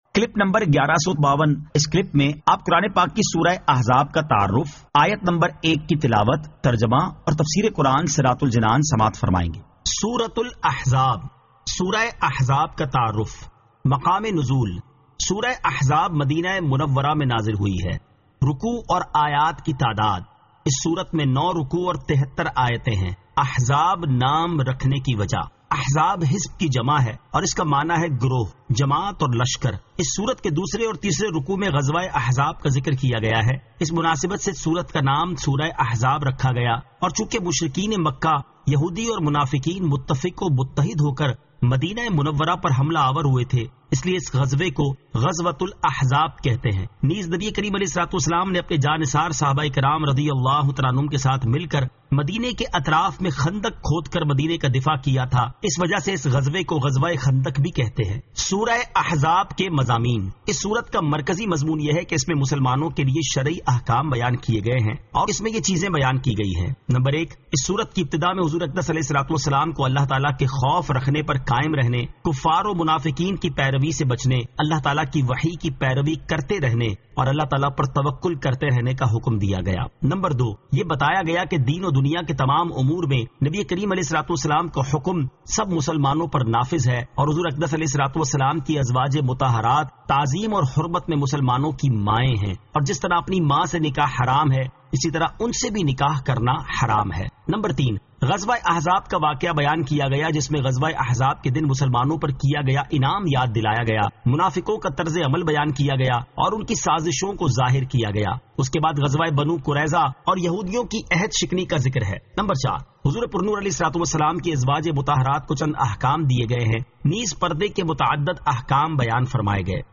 Surah Al-Ahzab 01 To 01 Tilawat , Tarjama , Tafseer